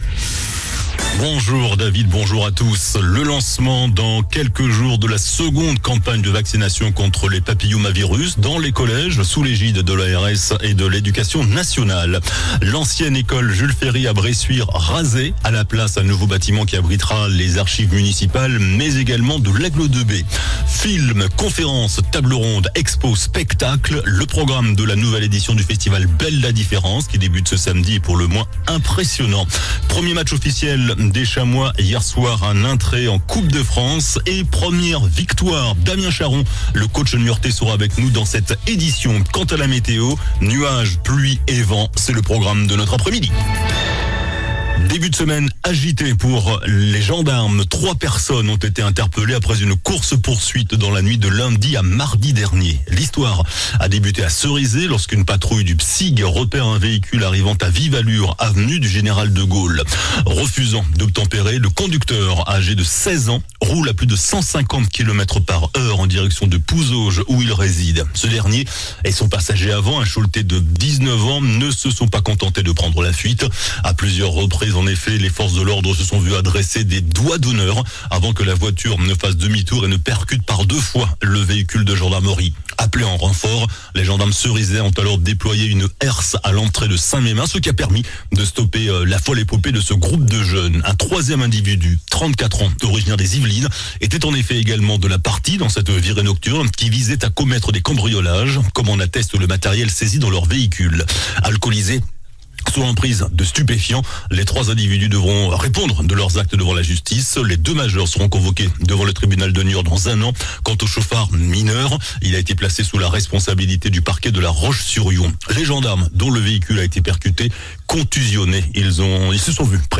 JOURNAL DU 26 SEPTEMBRE ( MIDI )